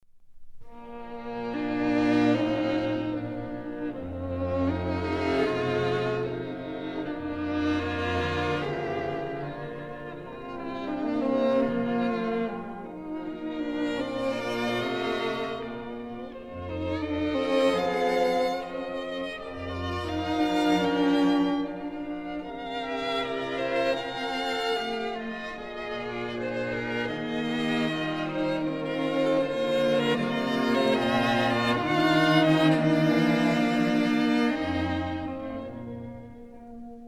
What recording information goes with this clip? Stereo recording made in Columbia Studios,30th Street, New York City